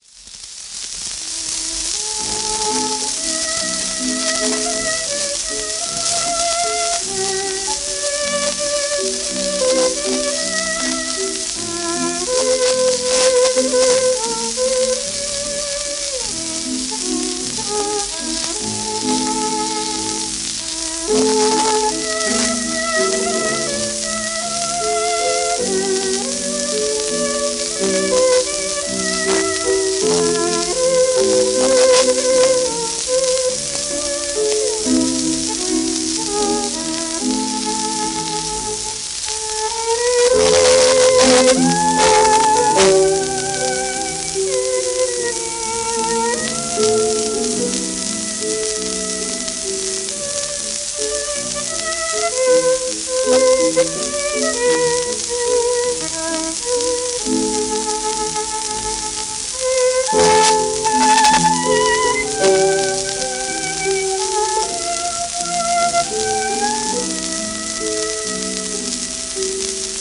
w/ピアノ